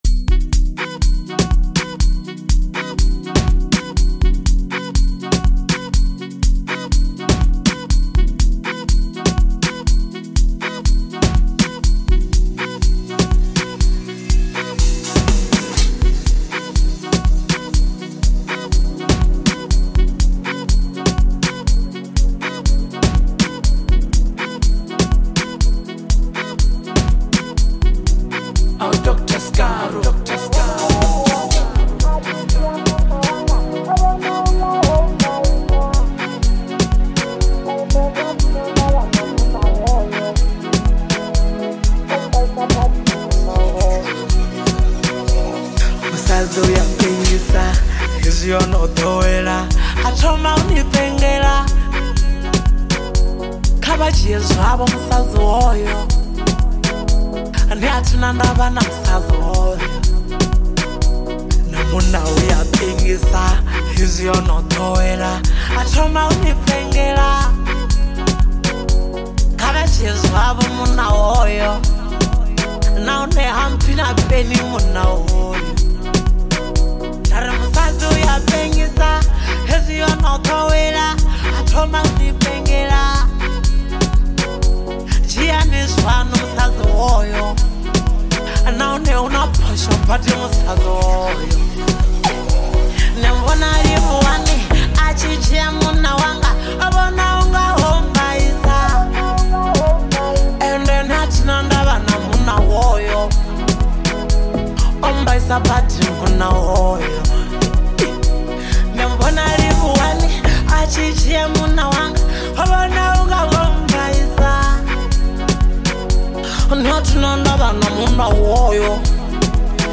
energetic rhythms